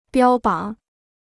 标榜 (biāo bǎng) Free Chinese Dictionary